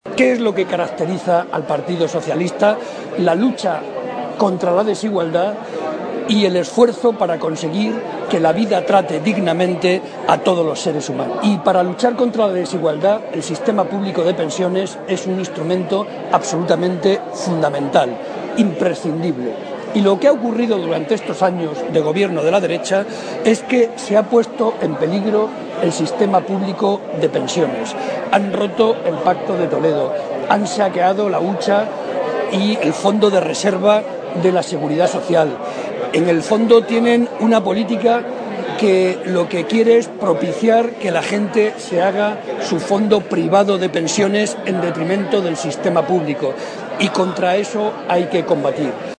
En acto público en Puertollano
Cortes de audio de la rueda de prensa